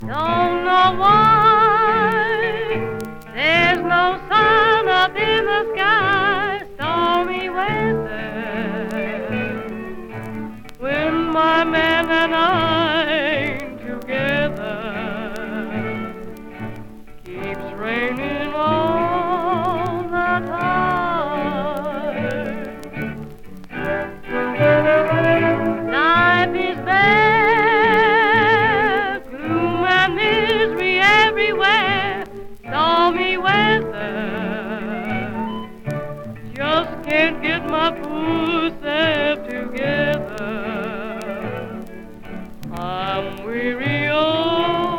Pop, Vocal, Staege & Screen　UK　12inchレコード　33rpm　Mono